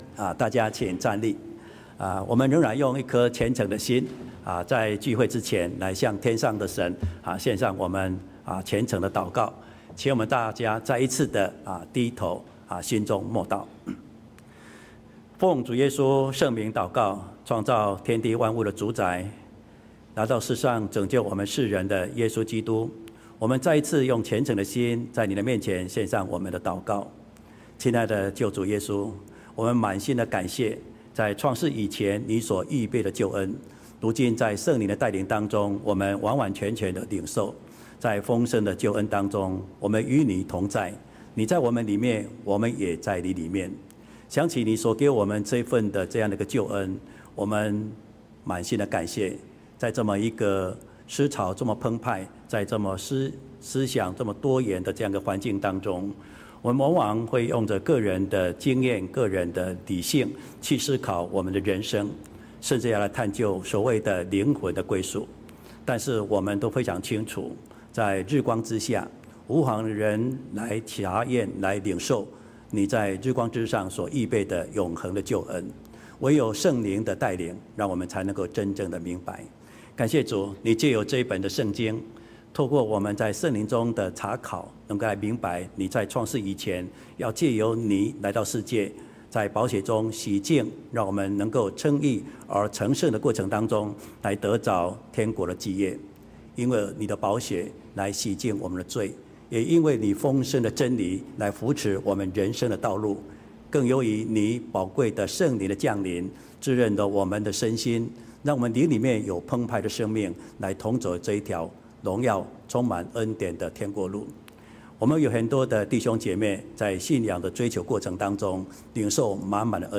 秋季靈恩佈道會：永生的食物-講道錄音